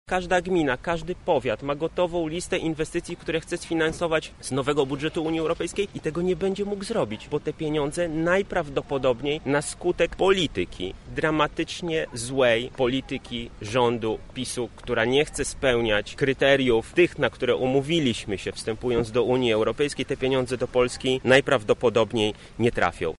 -mówi poseł Michał Krawczyk z Platformy Obywatelskiej.